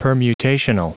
Prononciation du mot permutational en anglais (fichier audio)
Prononciation du mot : permutational